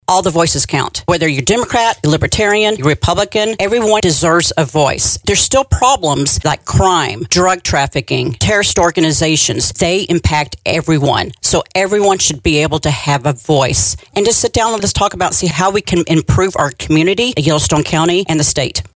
Republican Stacy Zinn is the newly appointed Yellowstone County Representative in House District 52 filling the seat of Bill Mercer who has been appointed as U.S. District Court Judge for the District of Montana. Today on Voices of Montana she told listeners how she’s approaching her new job.